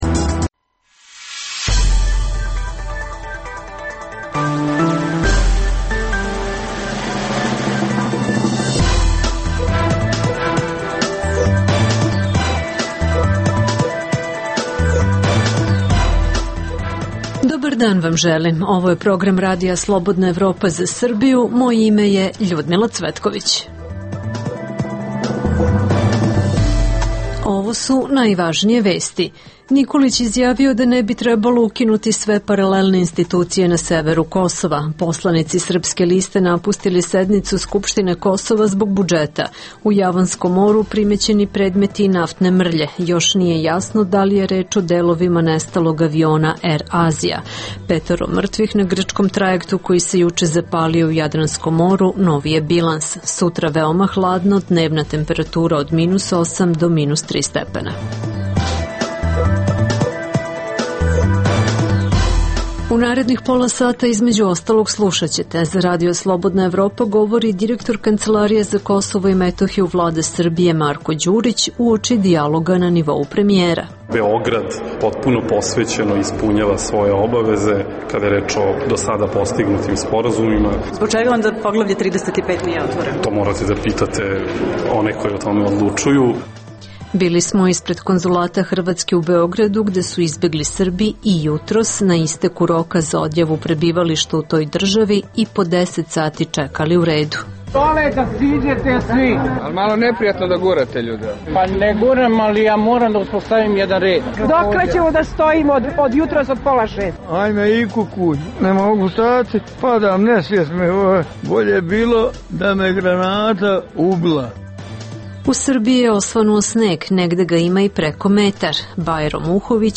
Beležimo atmosferu ispred Konzulata Hrvatske u Beogradu gde su izbegli Srbi i jutros, na isteku roka za odjavu prebivališta u toj državi, i po deset sati čekali u redovima.